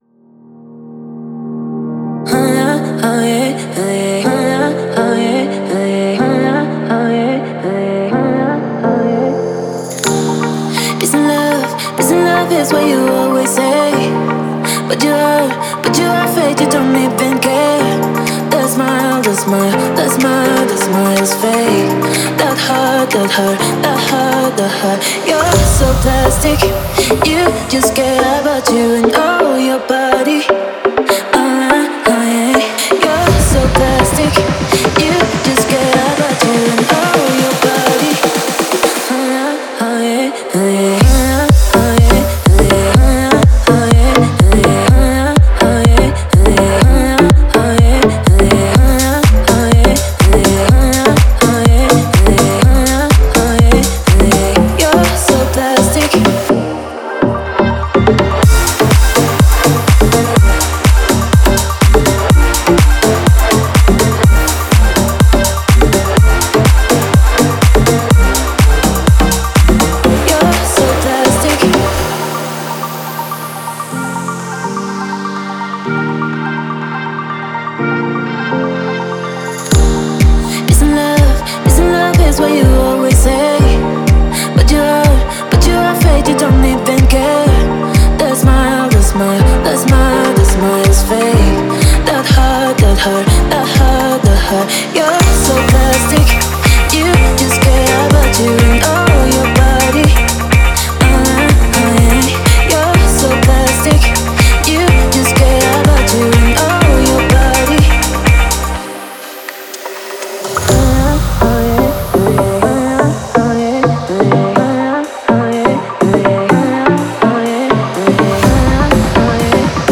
которая сочетает в себе элементы электронной музыки и попа.